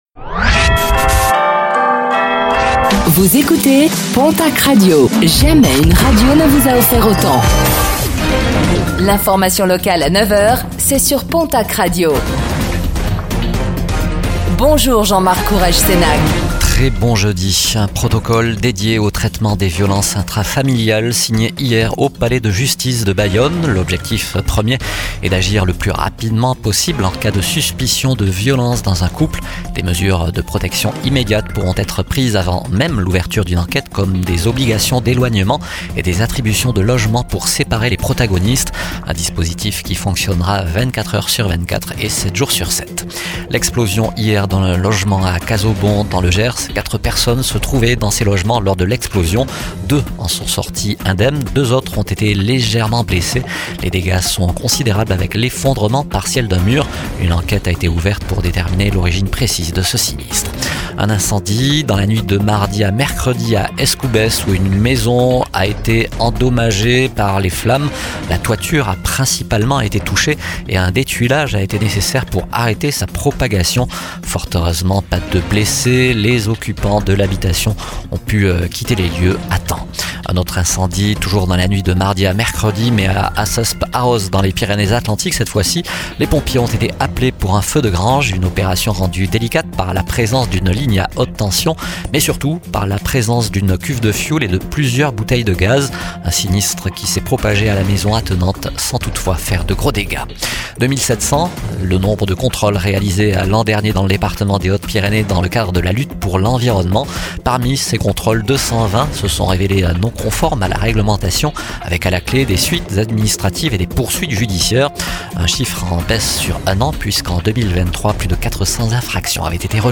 Réécoutez le flash d'information locale de ce jeudi 27 mars 2025